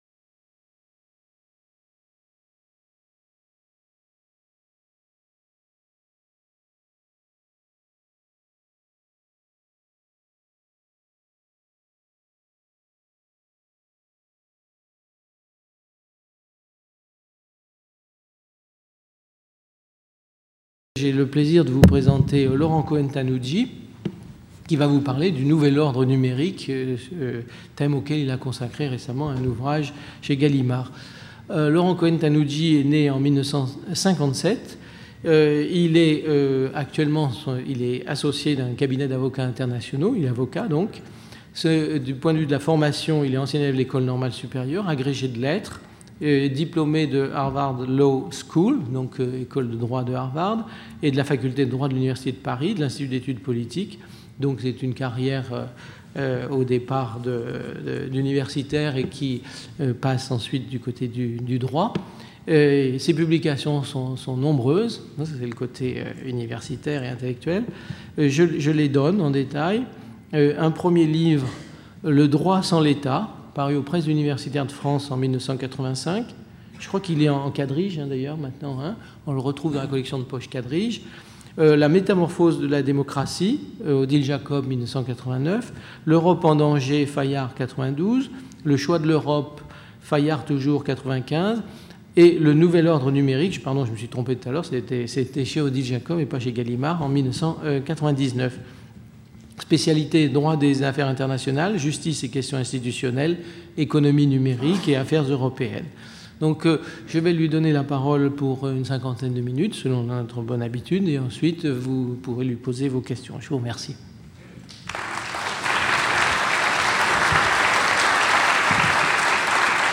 La conférence explicitera dans un premier temps les ressorts techniques, réglementaires et économiques de la " révolution numérique ". Elle analysera ensuite les caractéristiques principales de l'économie numérique, résultant de la convergence des industries des télécommunications, de l'information et de l'audiovisuel, et dont Internet est désormais le moteur.